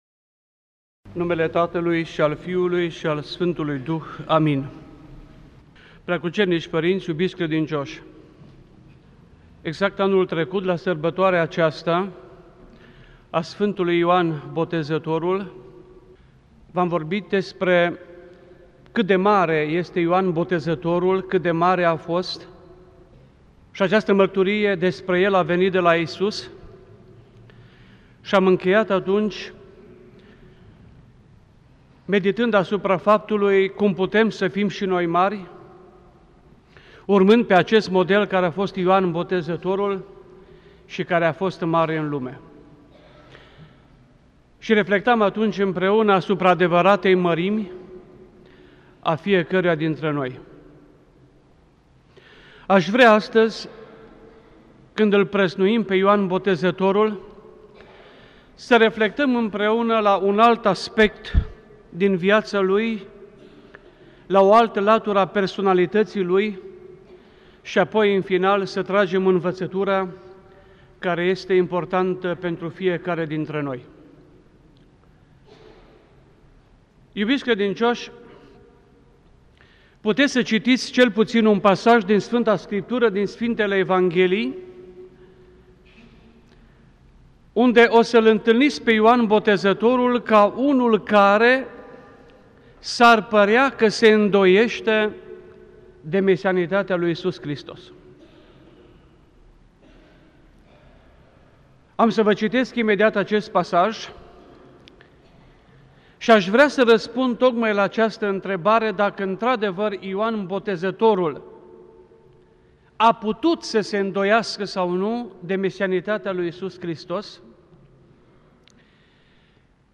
Predică la sărbătoarea soborului Sfântului Prooroc Ioan Botezătorul
rostit la sărbătoarea soborului Sfântului Prooroc Ioan Botezătorul, în anul 2006, la Catedrala